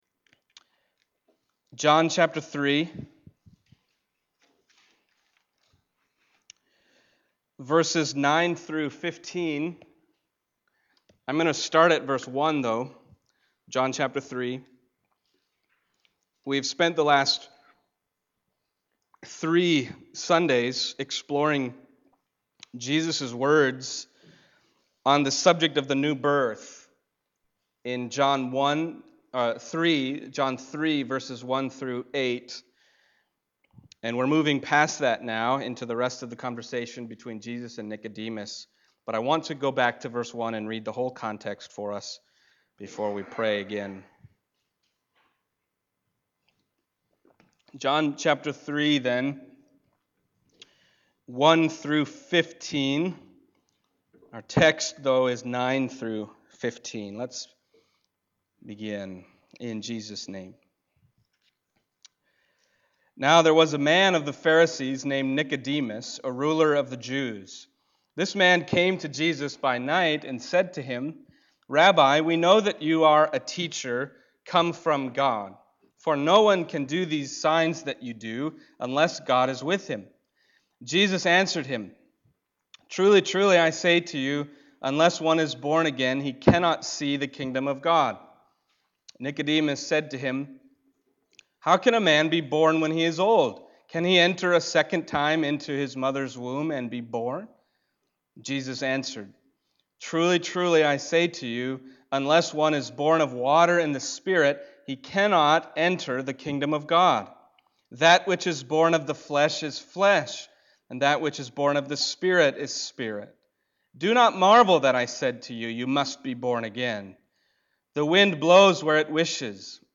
John Passage: John 3:9-15 Service Type: Sunday Morning John 3:9-15 « You Must Be Born Again